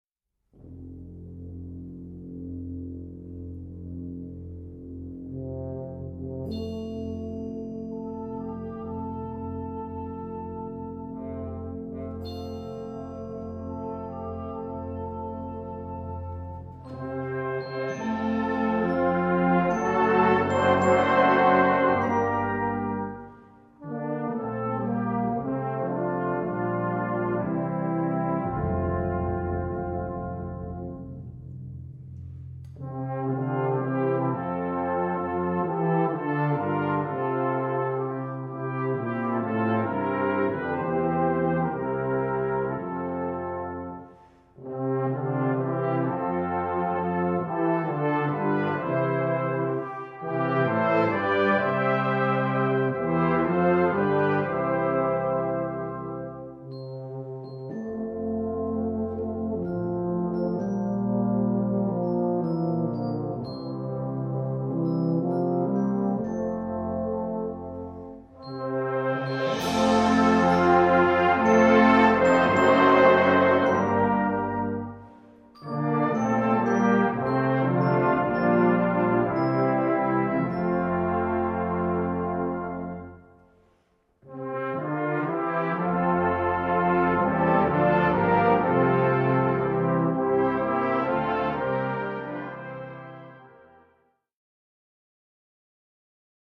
2:40 Minuten Besetzung: Blasorchester Tonprobe